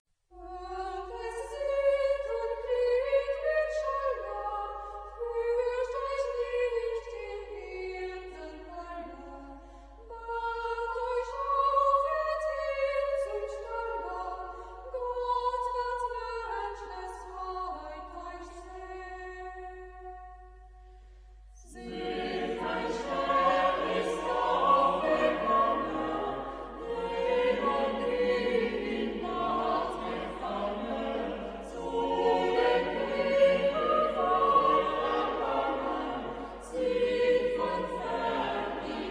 Genre-Style-Forme : Sacré ; Renaissance ; Motet
Type de choeur : SATB  (4 voix mixtes )
Tonalité : fa majeur